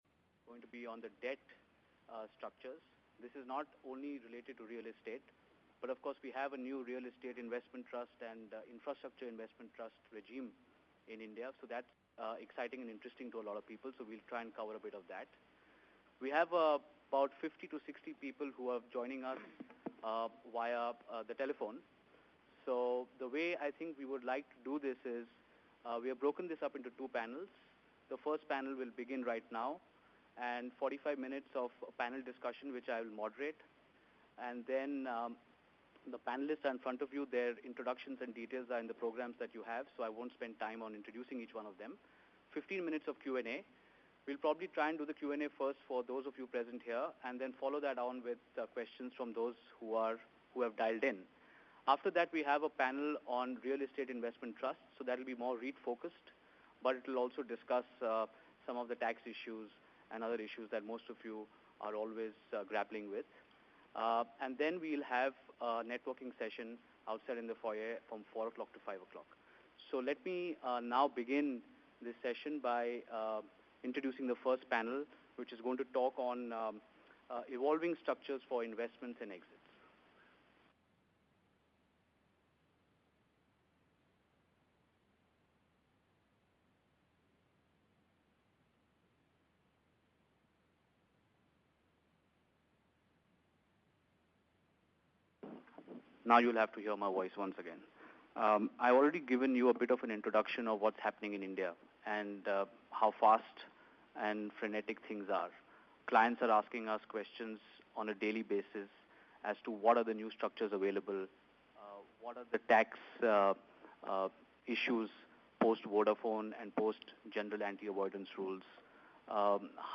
Round Table + Webinar: Innovative Structures and Strategies for Investing into India (Thursday, August 21, 2014)